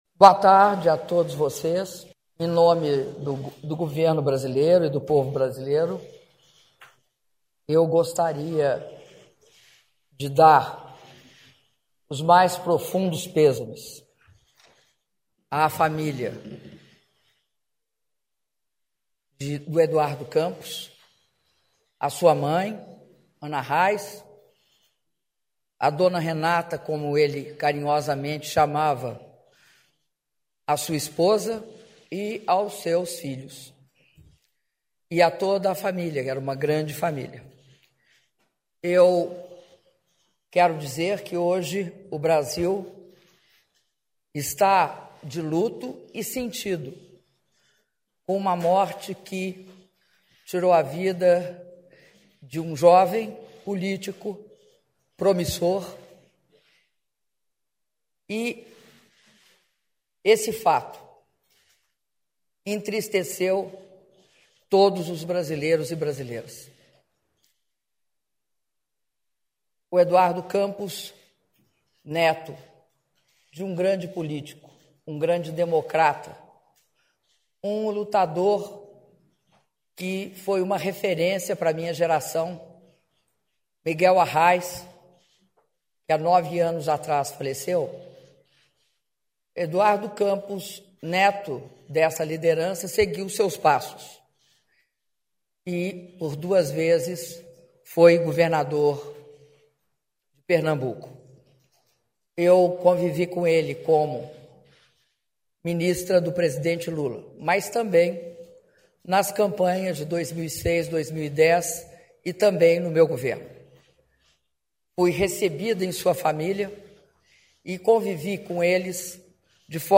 Áudio da declaração da Presidenta da República, Dilma Rousseff, sobre a morte de Eduardo Campos (06min15s)